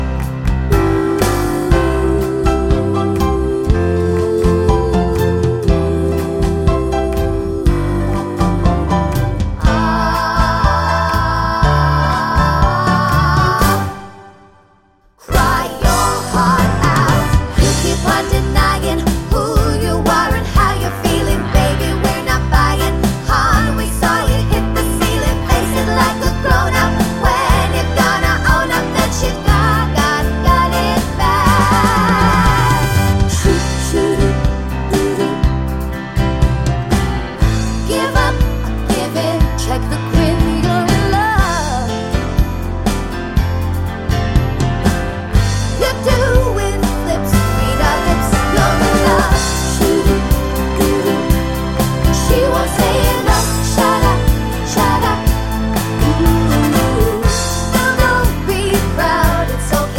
no Backing Vocals Soundtracks 2:18 Buy £1.50